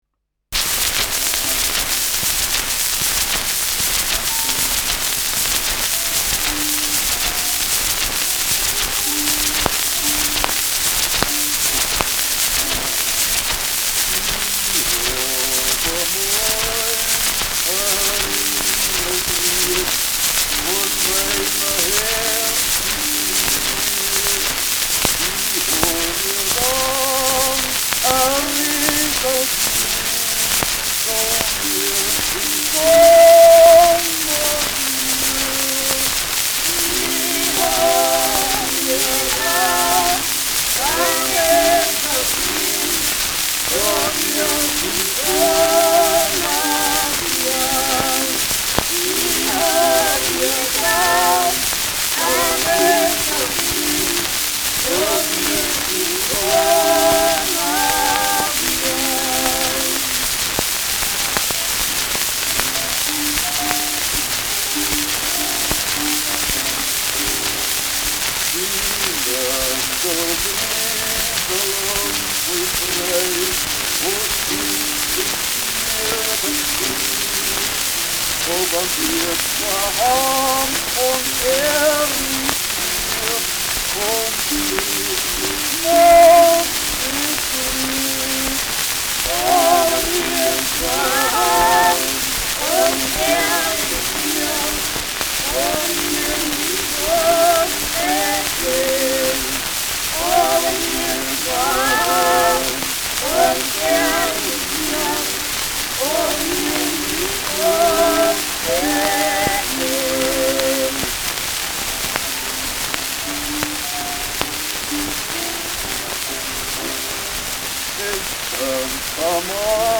Schellackplatte
sehr starkes Rauschen : Knacken : starkes Knistern : abgespielt : leiert
Teufel-Pühringer-Terzett (Interpretation)